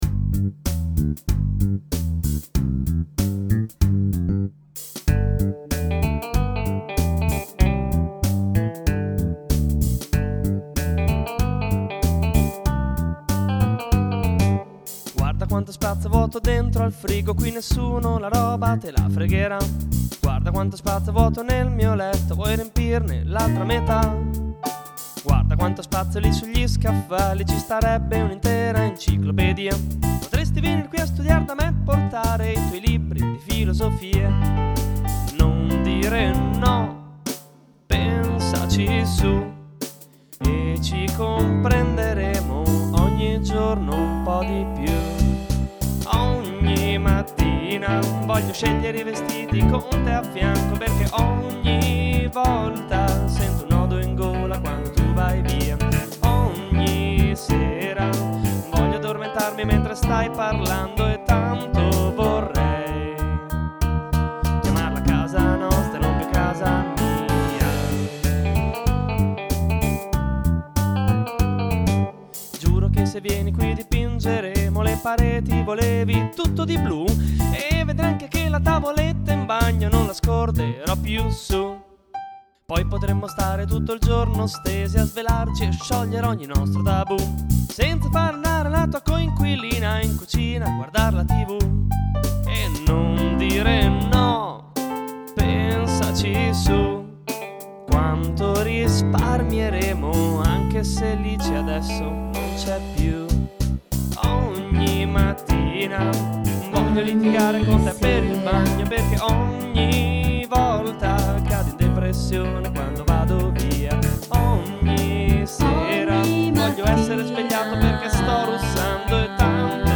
Luogo esecuzioneIn salotto :)
GenereWorld Music / Country